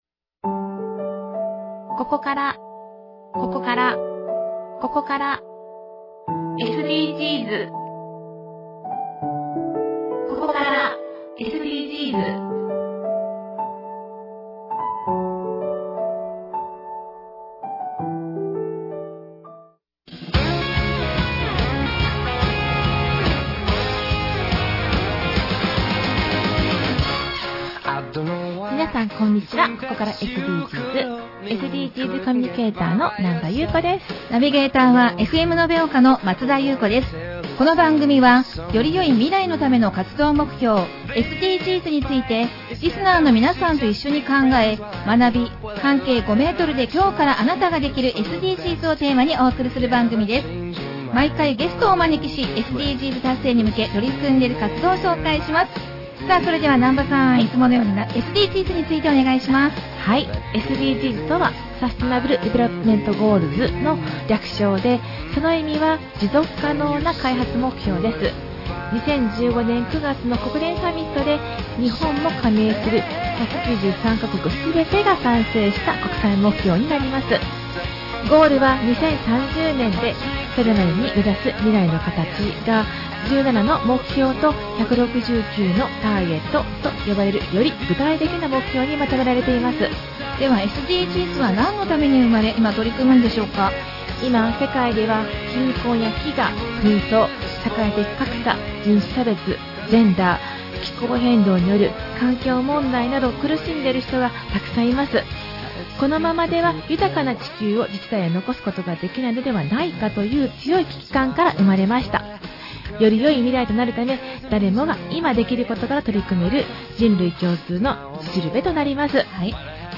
特別番組
ゲスト 延岡市長 読谷山洋司さん